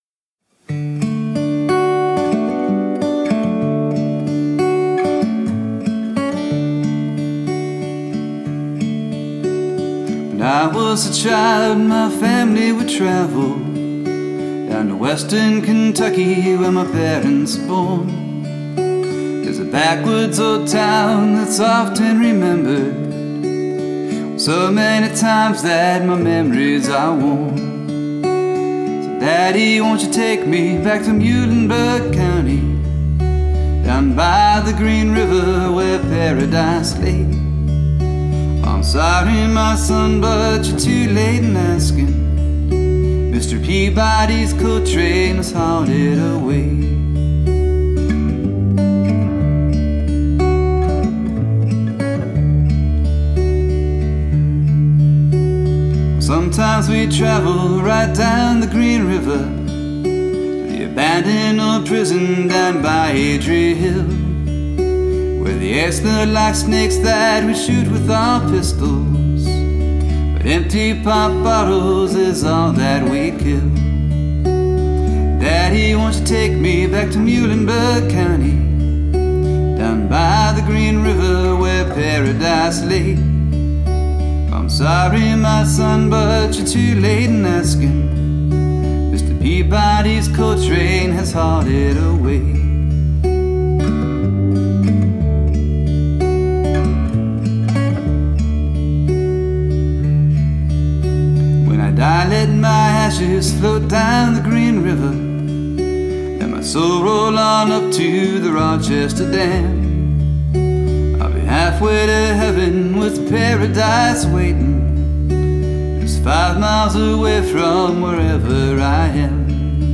And thanks for letting me cover your song: